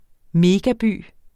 Udtale [ ˈmeːgaˌbyˀ ]